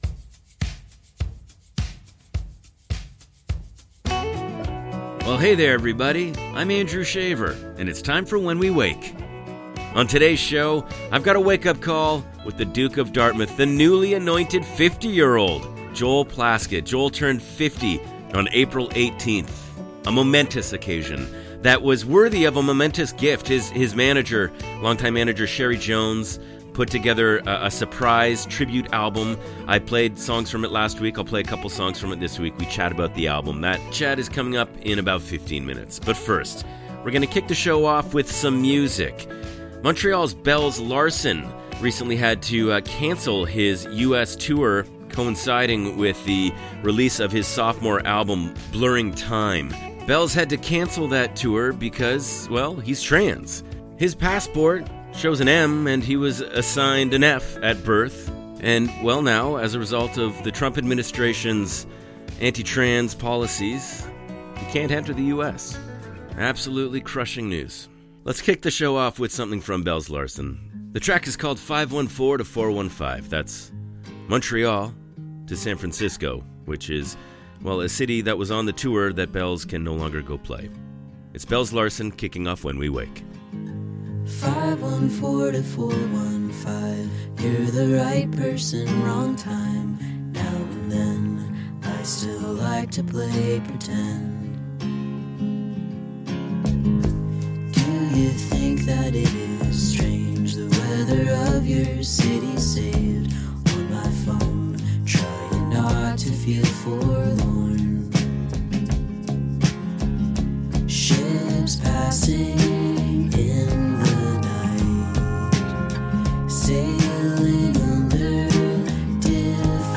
Includes 30 minute Interview with Joel Plaskett